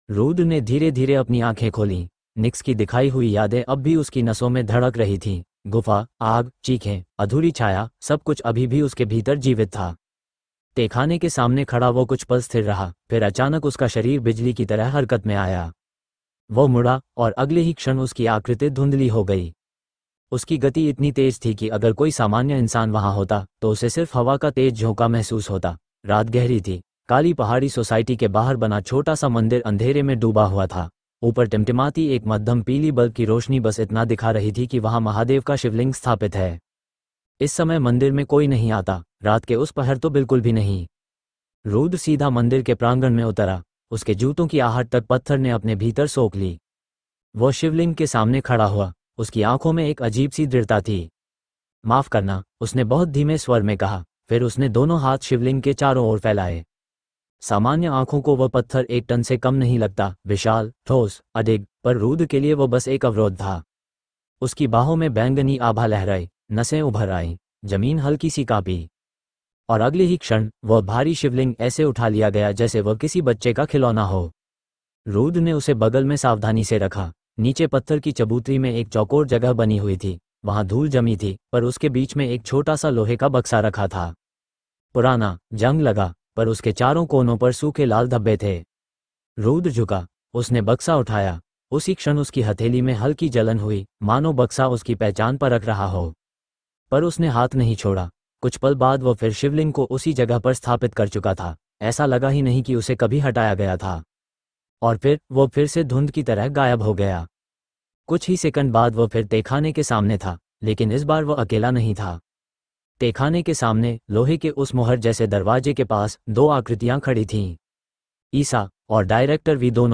AudioTaleFM – Premium Sci-Fi, Fantasy & Fairy Tale Audio Stories